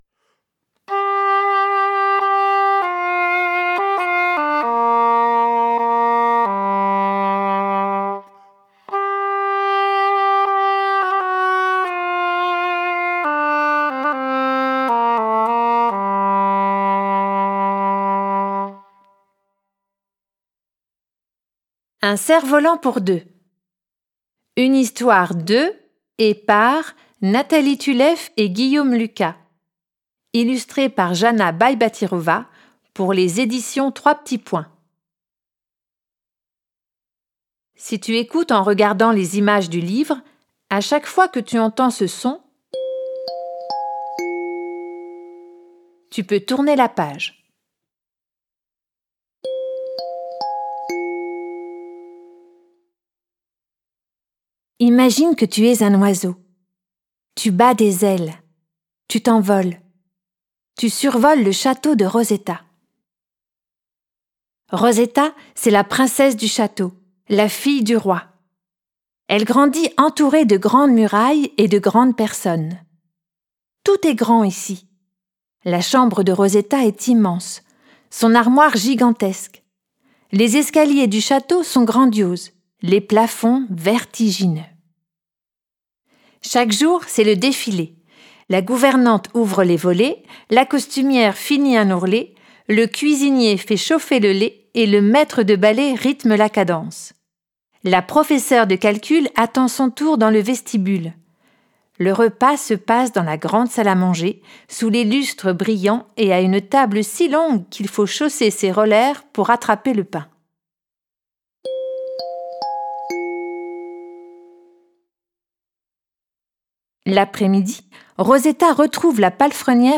Une histoire virevoltante qui illustre combien c’est mieux d’être à deux. Sur fond d’entraide, de bienveillance et d’écologie, les deux ami·e·s s’apprivoisent au fil des musiques de Beethoven, de Korsakov, de chants populaires turcs et de morceaux originaux ponctués de notes de piano, de hautbois, de cor anglais, de doudouk, de métallophone, de flûte à coulisse, de clochettes et de kalimba.